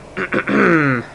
Clear Throat Sound Effect
Download a high-quality clear throat sound effect.
clear-throat-1.mp3